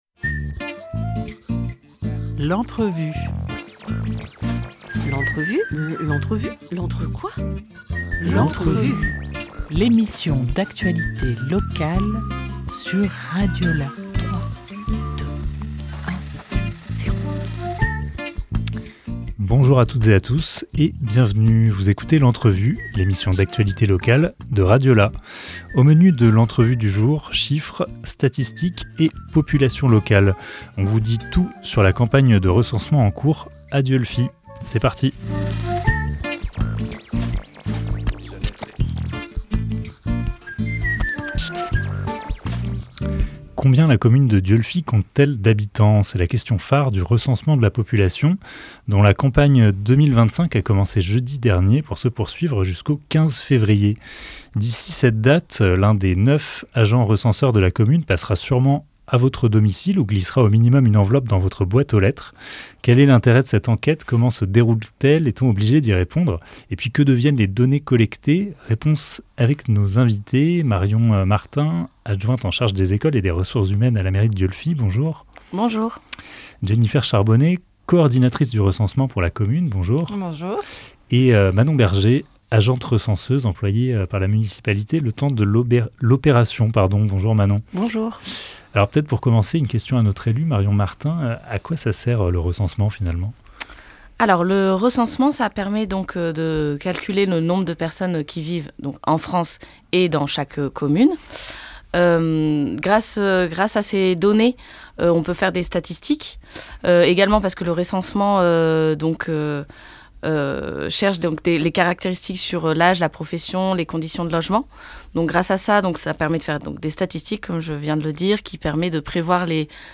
21 janvier 2025 17:13 | Interview